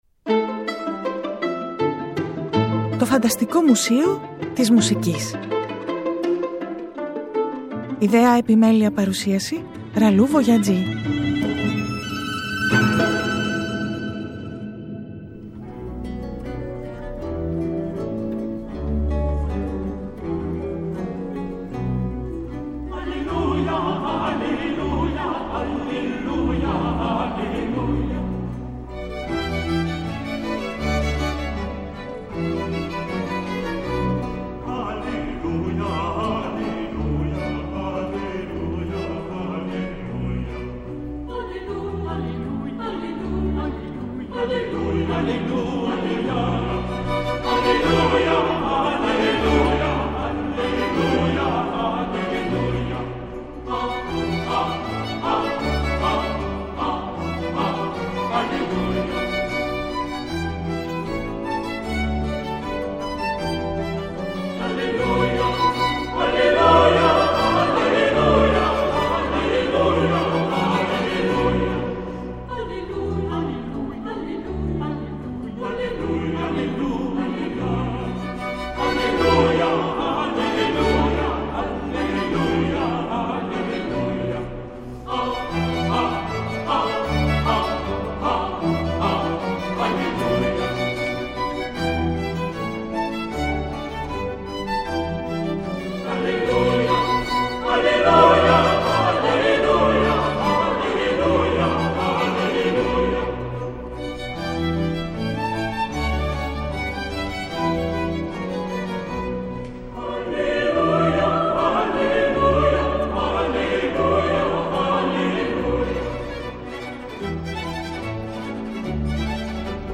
χορωδιακό καντάτας
κοντσέρτο για ξύλινο πλάγιο φλάουτο
σερενάτας για σύνολο πνευστών
ένα κομμάτι για πιάνο που γίνεται τραγούδι